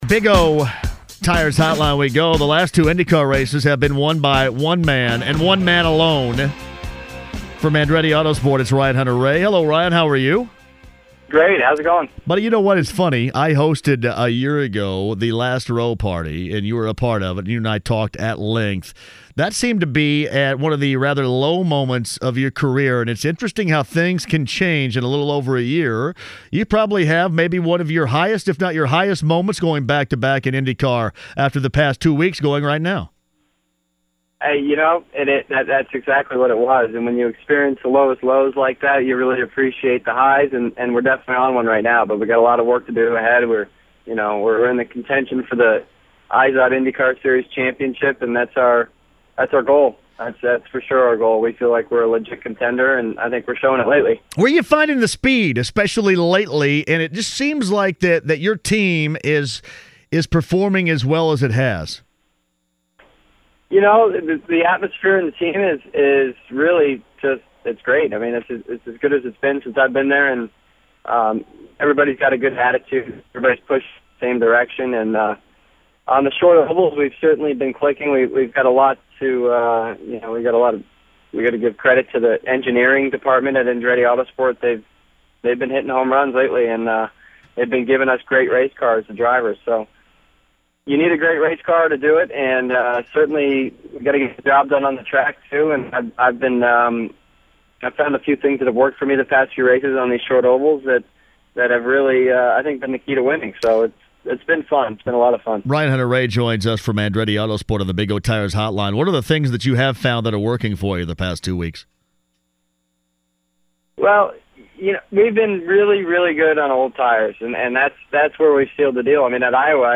WFNI ESPN 1070: The Fan – Ryan Hunter-Reay Interview